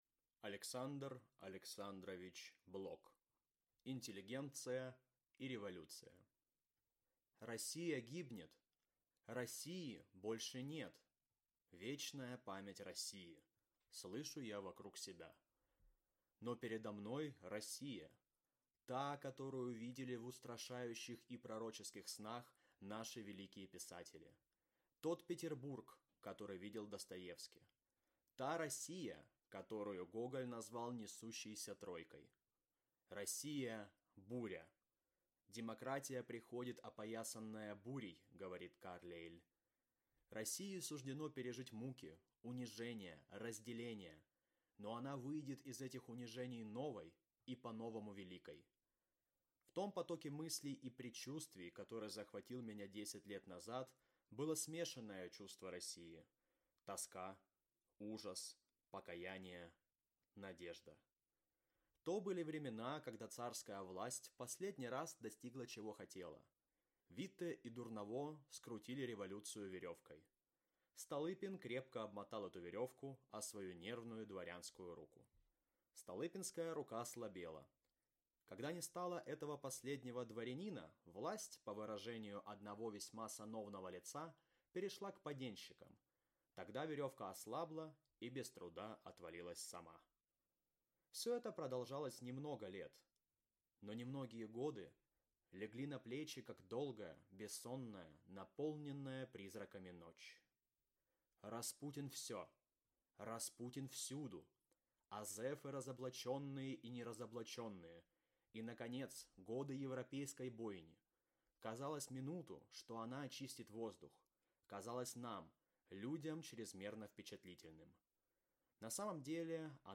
Аудиокнига Интеллигенция и Революция | Библиотека аудиокниг